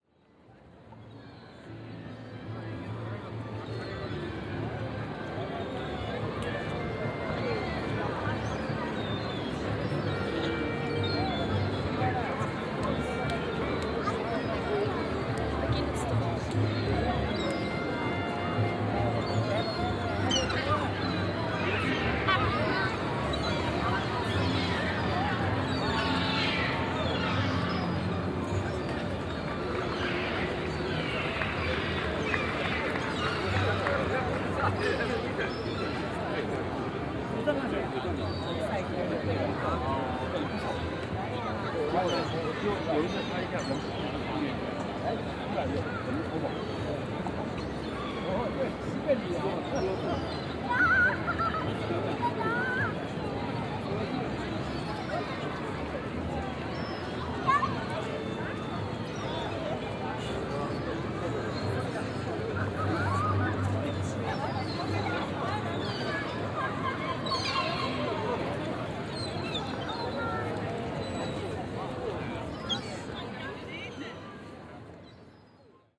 Het geluid blijft daardoor langer hangen, wat met name bij drukte (stemmen, voetstappen, rolkoffers, muziek) kan leiden tot een verhoogd geluidsniveau.
Walking in Piazza San Marco.
Daarbij dragen de hoge, harde gevels van marmer en steen van de omringende architectuur, het geringe aantal geluidsabsorberende elementen (geen gras, weinig bomen), en de grootte van het plein bij aan de reflectie van geluid (veel echo en galm).